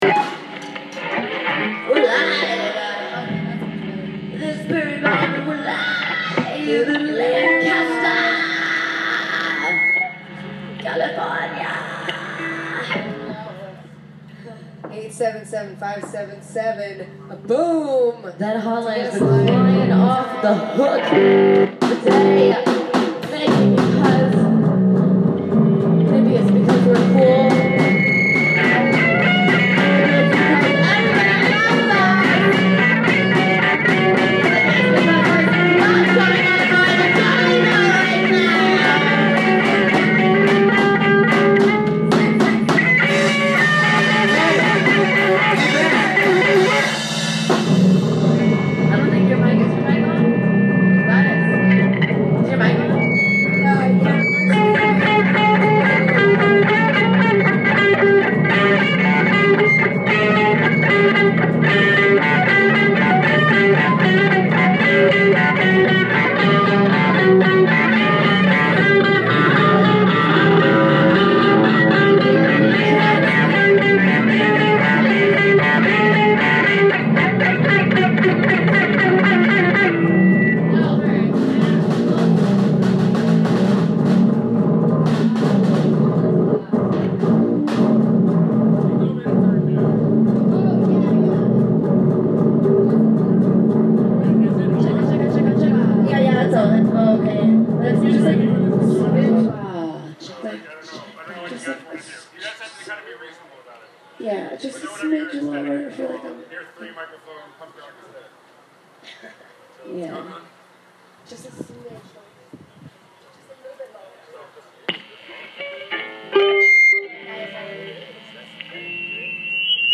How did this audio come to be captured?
live from our backyard, straight outta Florida (after like 30 tour dates) ... honed and stoned